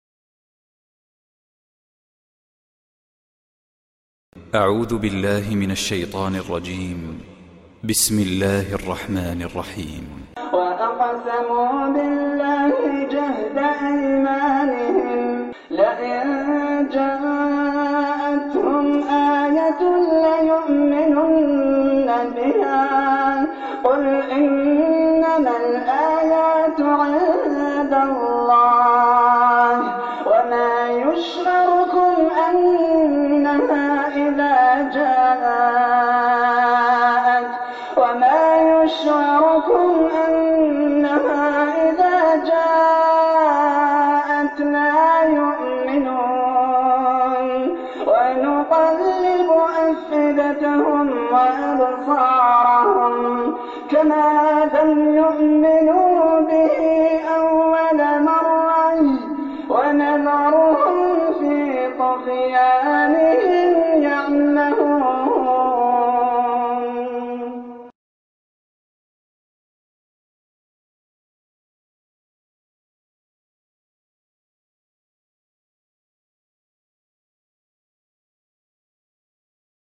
صوت مؤثر جدا للقارئ